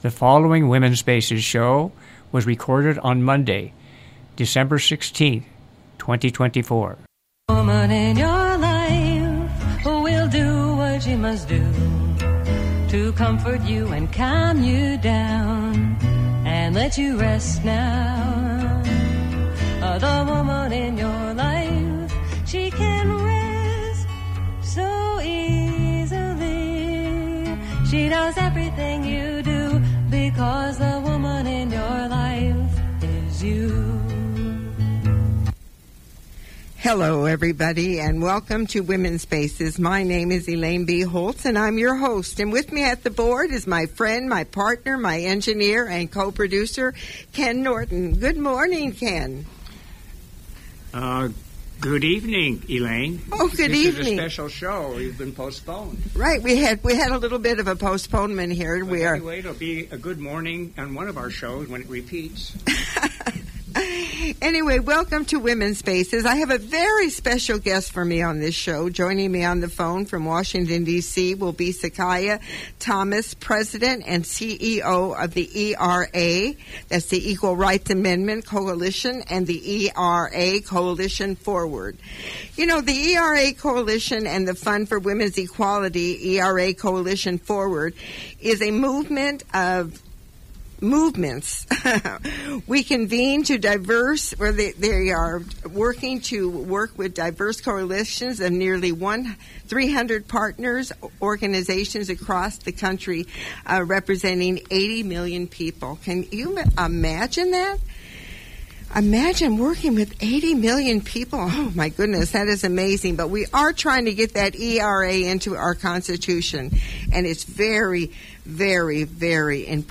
I have a special guest for this show, joining me on the phone from Washington DC